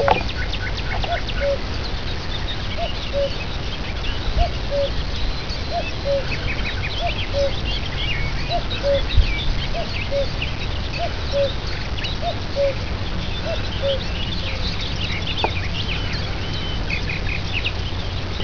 Kuckuck
Abb. 01: In der schönen Maisonne am Ufer der Oker hört man den Ruf des Kuckucks.
Abb. 02: Die Frequenzanalyse zeigt den Ruf des Kuckucks und darüber bei höheren Frequenzen weitere Sänger.
Frequenzen des Kuckucks     oberer Ton (chirp):  720 bis 570   und   unterer Ton: 570 Hz
Darüber in der ersten Sekunde  mehrere Chirp von 2200 bis 1400  und   von 5500 bis 2400 Hz.